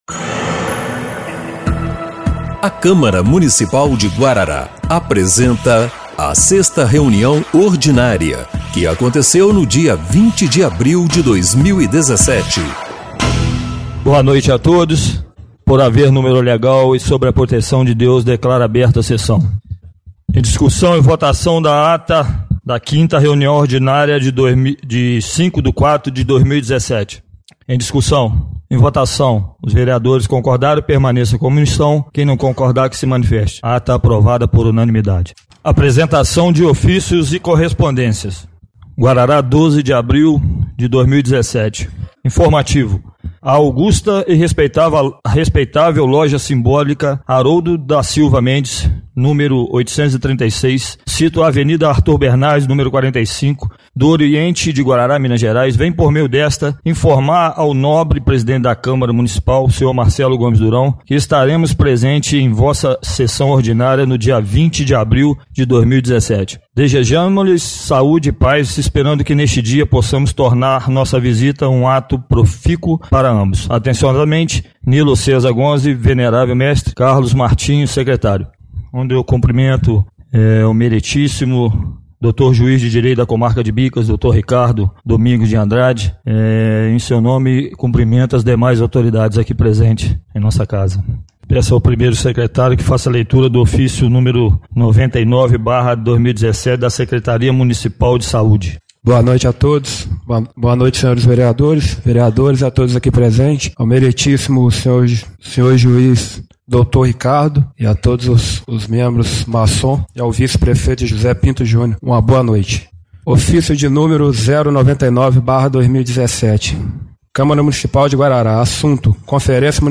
6ª Reunião Ordinária de 20/04/2017